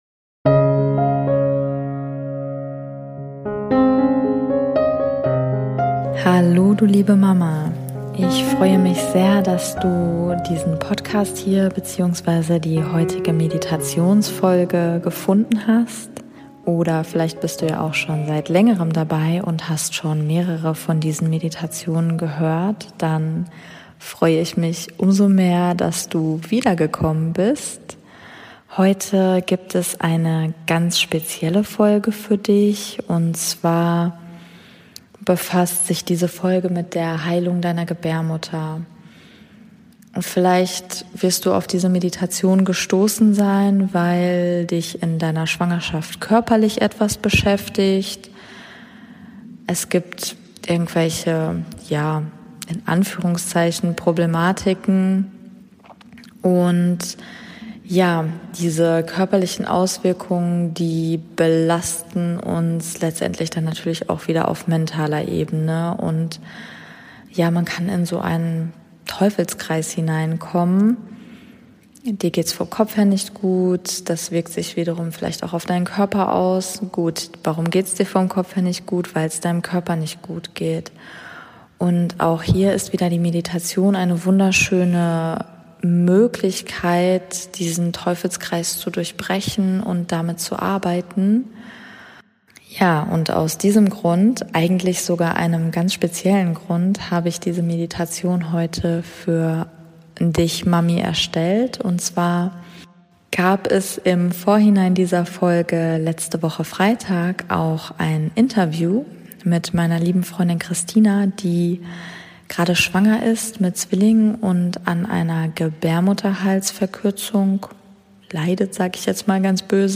#006 - Meditation Heilung deiner Gebärmutter ~ Meditationen für die Schwangerschaft und Geburt - mama.namaste Podcast